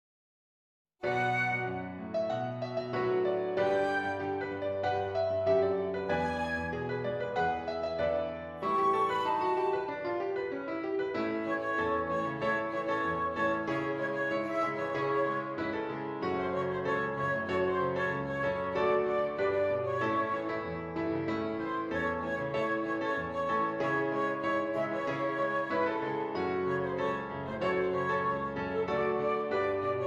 Flute Solo with Piano Accompaniment
A Major
Fast